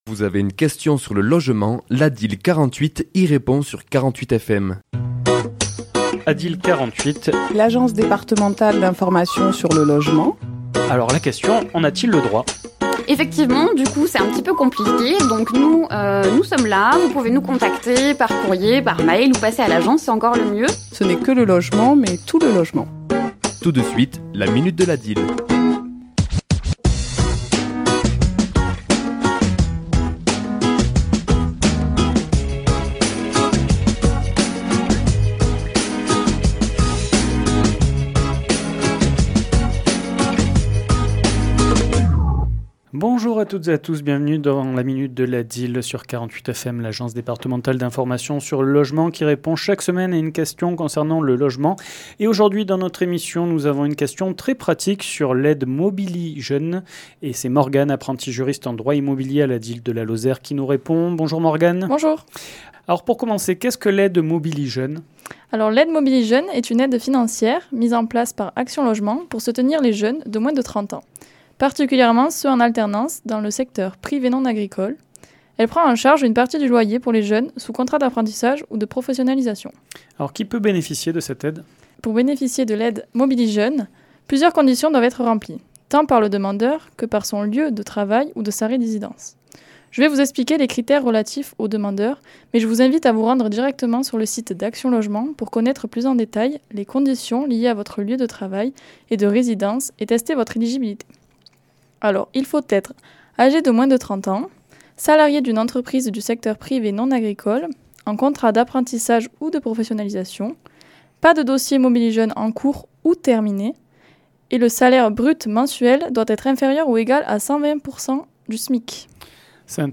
Chronique diffusée le mardi 3 décembre à 11h et 17h10